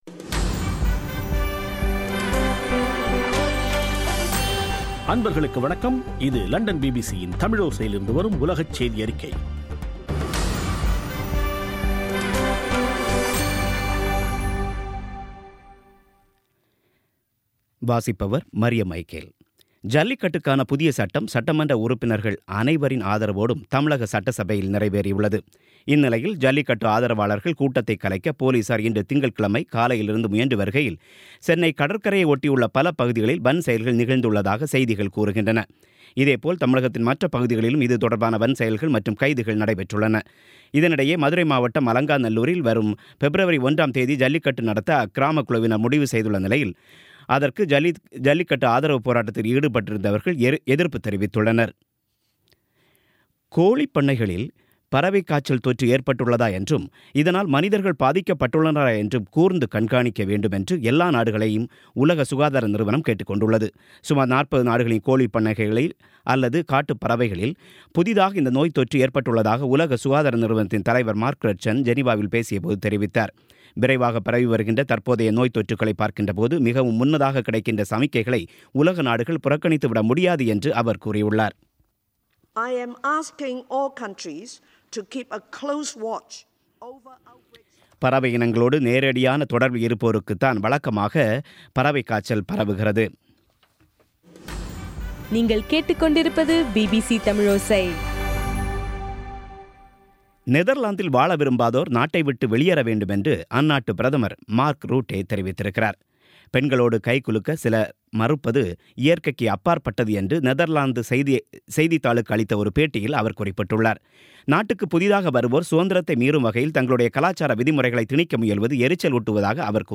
பிபிசி தமிழோசை செய்தியறிக்கை (23/01/2017)